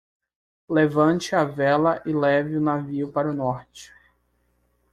Read more Noun Verb velar Adj Noun Verb Verb Read more Frequency C1 Pronounced as (IPA) /ˈvɛ.lɐ/ Etymology Inherited from Latin vigilia Borrowed from Latin vēla In summary Deverbal from velar.